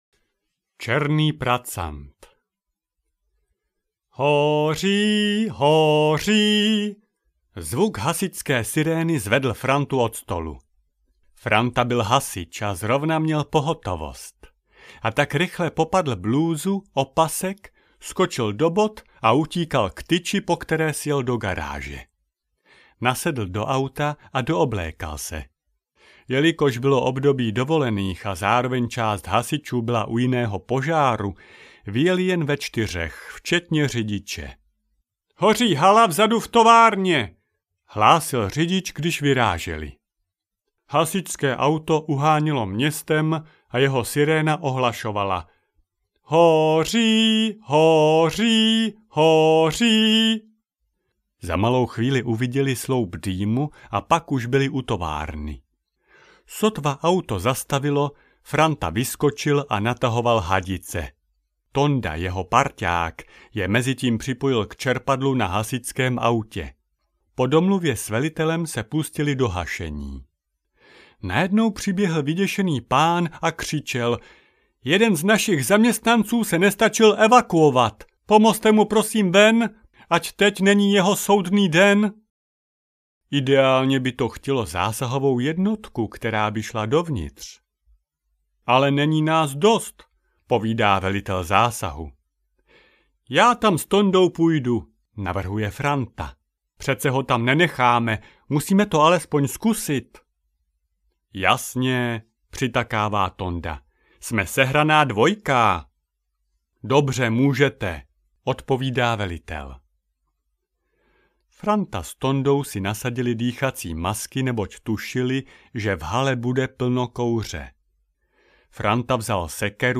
Pohádky z tátovy hlavy audiokniha
Ukázka z knihy